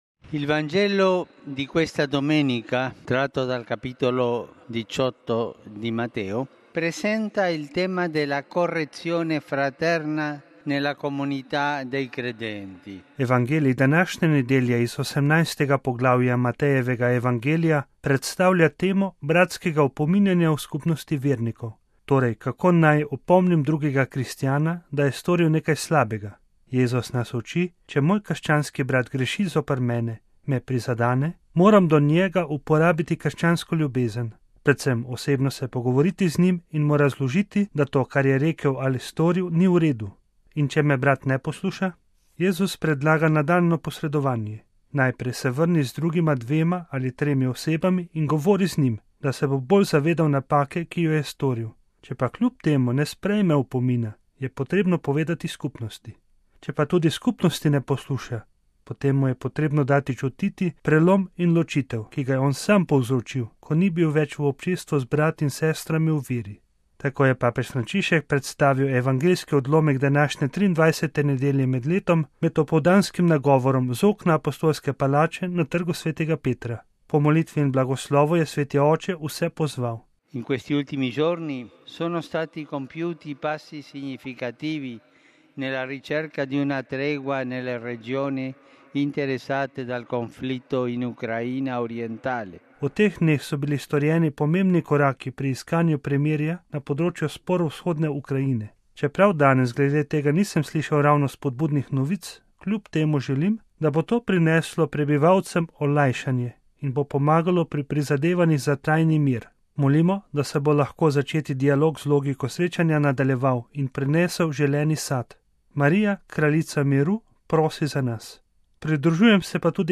Tako je papež Frančišek predstavil evangeljski odlomek današnje 23. nedelje med letom med opoldanskim nagovorom z okna apostolske palače na Trgu sv. Petra.